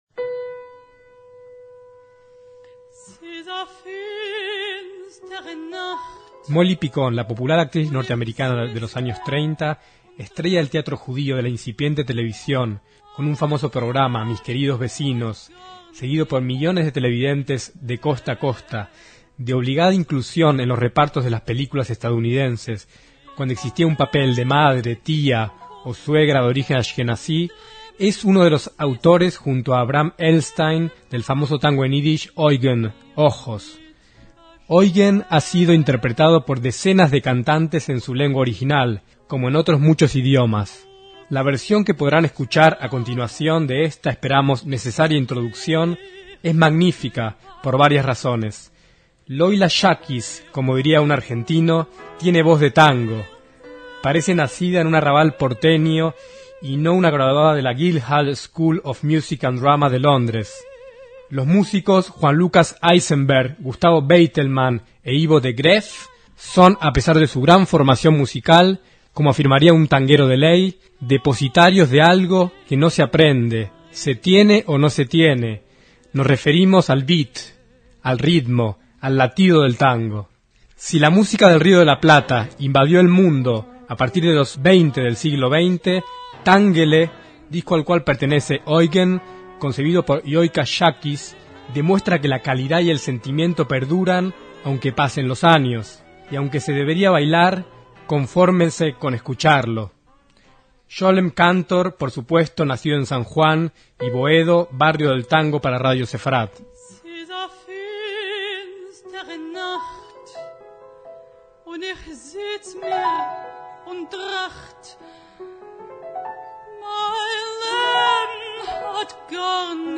la música cantada en ídish